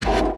cp-anim6-close.ogg